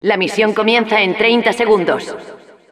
La Narradora
Announcer_begins_30sec_es.wav